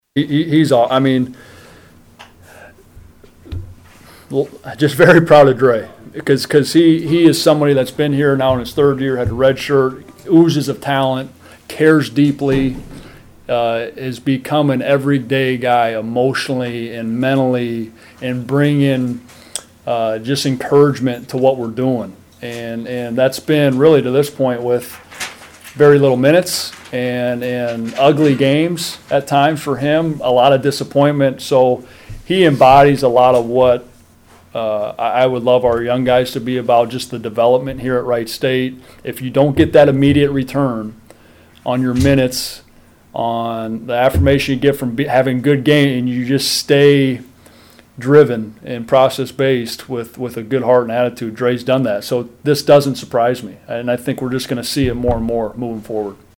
POSTGAME PRESS CONFERENCE EXCERPTS